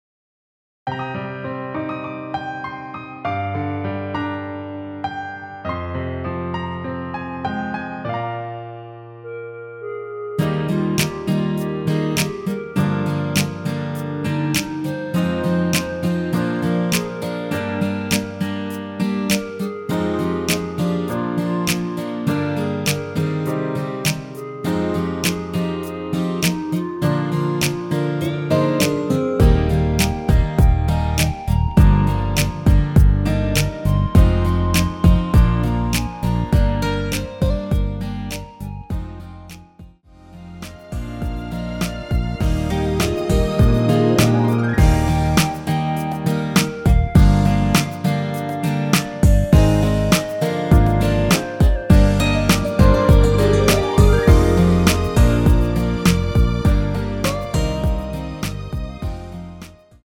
1절후 클라이 막스로 바로 진행되며 엔딩이 너무 길어 4마디로 짧게 편곡 하였습니다.
Eb
노래방에서 노래를 부르실때 노래 부분에 가이드 멜로디가 따라 나와서
앞부분30초, 뒷부분30초씩 편집해서 올려 드리고 있습니다.